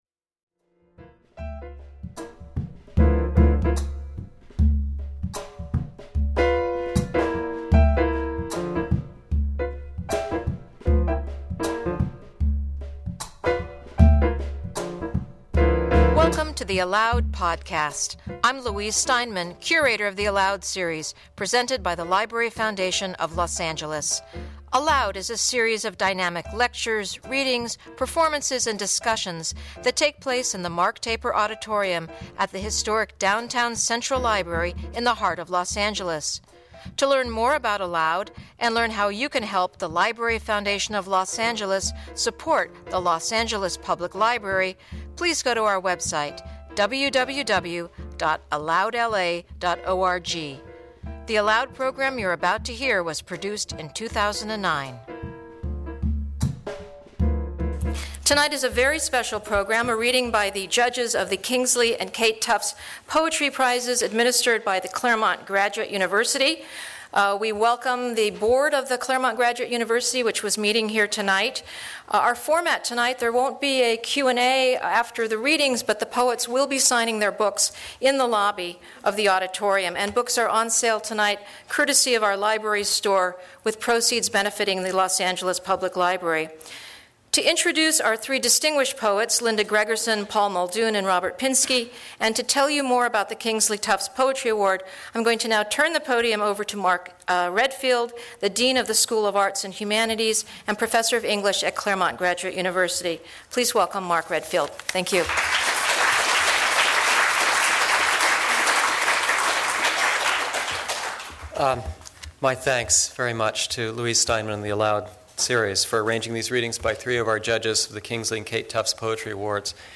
Linda Gregerson, Paul Muldoon, and Robert Pinsky: Three Kingley Tufts Prize Judged Read from Their Own Poetry
Three members of the final judging panel for the Kingsley and Kate Tufts Poetry Awards, read from their own prize-winning work.